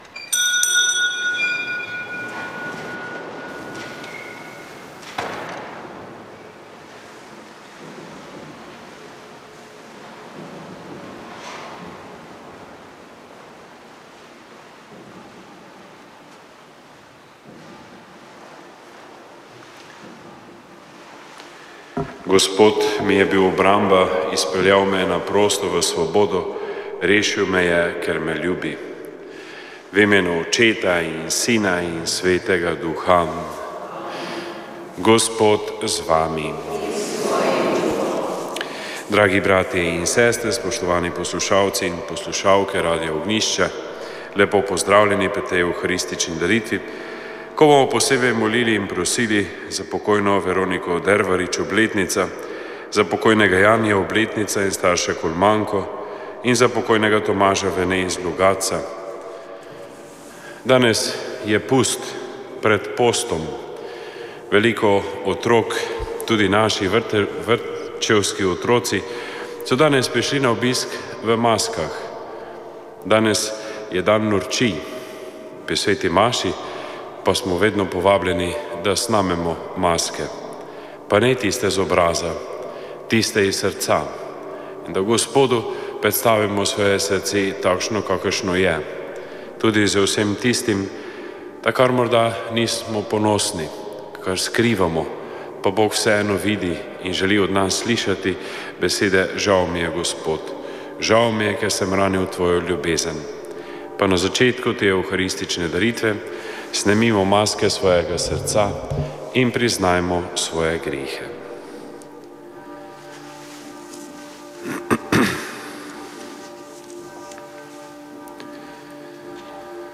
Sv. maša iz cerkve Marijinega oznanjenja na Tromostovju v Ljubljani 5. 3.